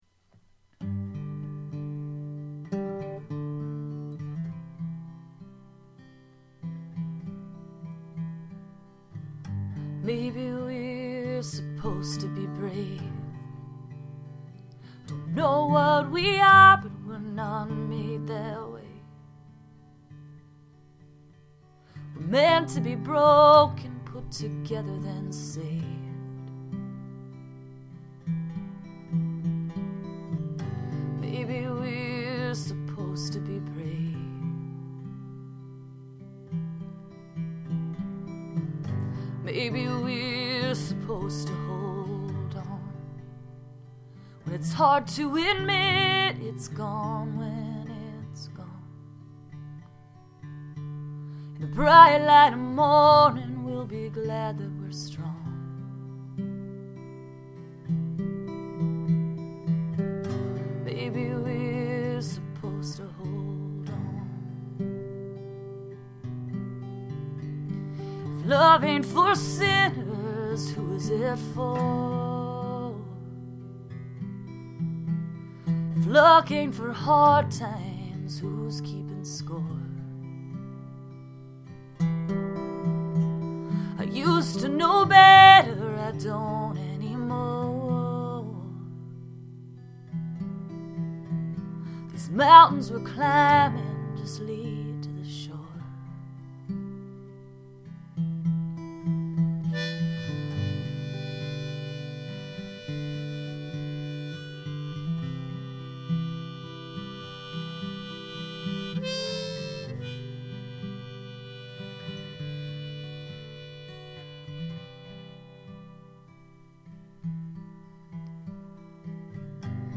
The living room sessions
Living Room Session